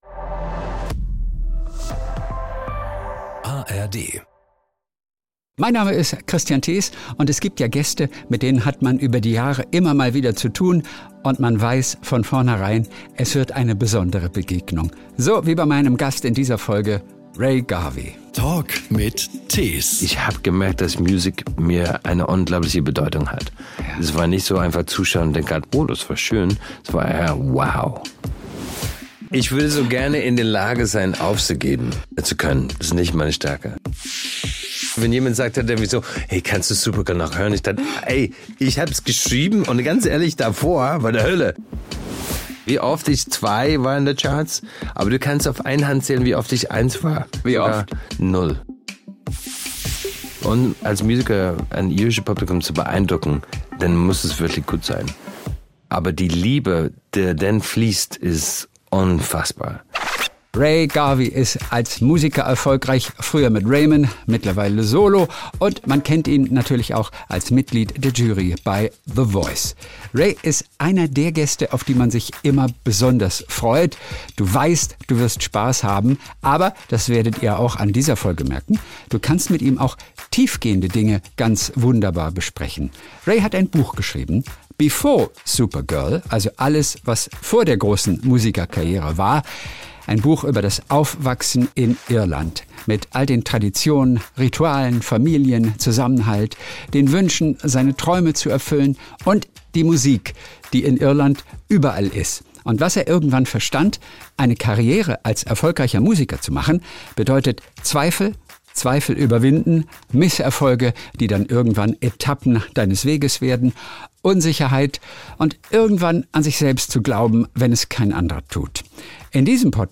Jeden Sonntag unterhält er sich mit Stars, Promis und interessanten Menschen verschiedener Branchen. Kristian hat einfach Lust auf seine Gesprächspartner und spricht über die besonderen Geschichten der Popstars, Schauspieler, Autoren & Co..